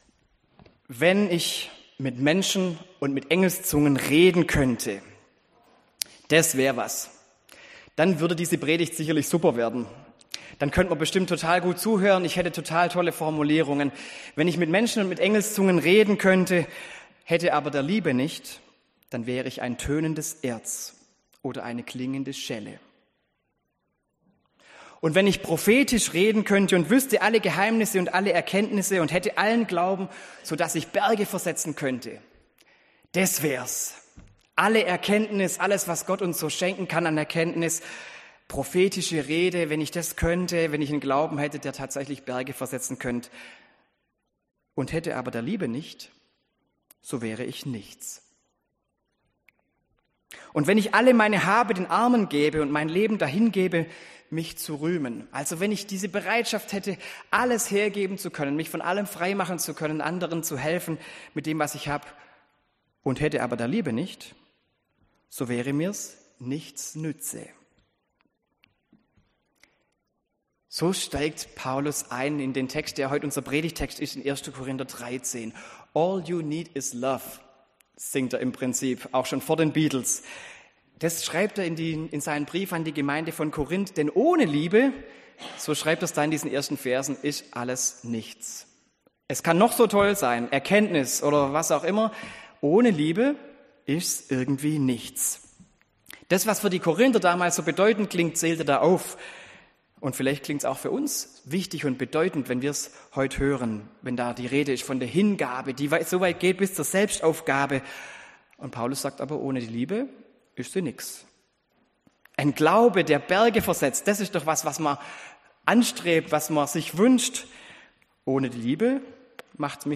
Predigt an Estomihi nach Gebet des Hymnus aus Römer 8 und Schriftlesung aus 1. Johannes 4,7-16.